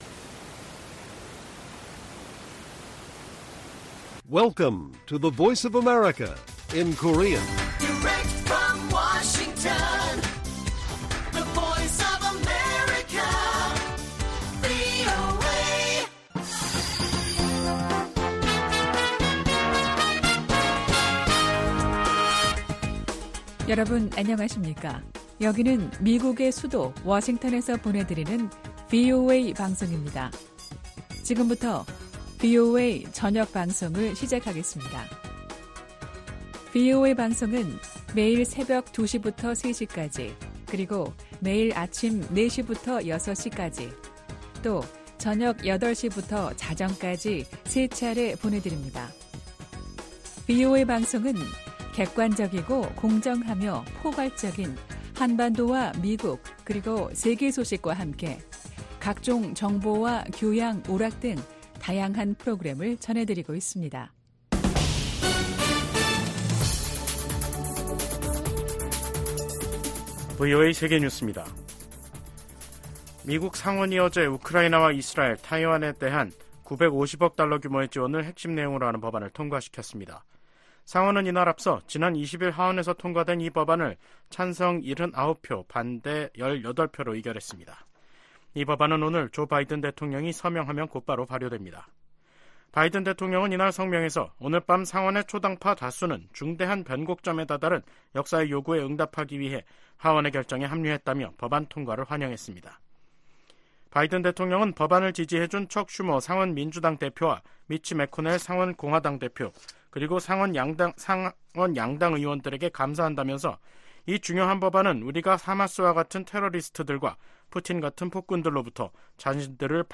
VOA 한국어 간판 뉴스 프로그램 '뉴스 투데이', 2024년 4월 24일 1부 방송입니다. 미 국무부는 북한의 핵반격훈련 주장에 무책임한 행동을 중단하고 진지한 외교에 나서라고 촉구했습니다. 미 국방부는 역내 안보를 위해 한국, 일본과 긴밀히 협의하고 있다고 밝혔습니다. 북한의 김여정 노동당 부부장은 올들어 이뤄진 미한 연합훈련 모두를 비난하며 핵 무력을 계속 비축하겠다고 밝혔습니다.